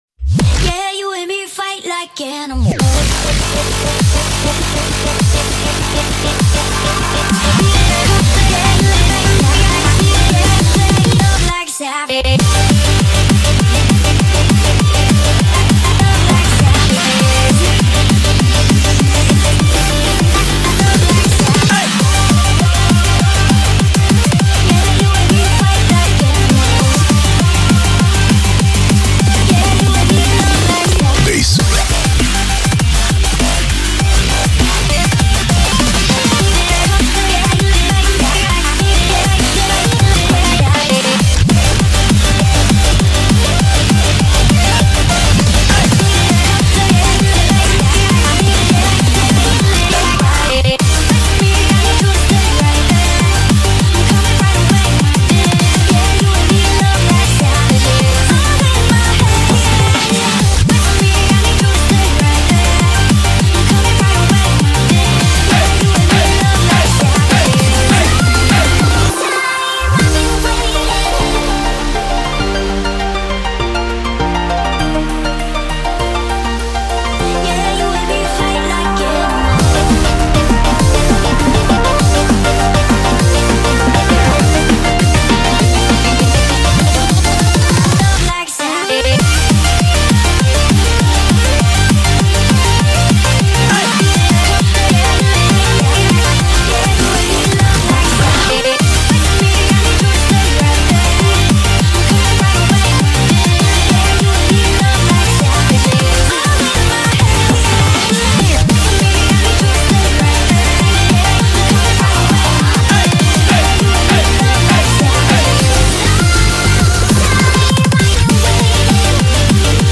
BPM200-400
Audio QualityPerfect (Low Quality)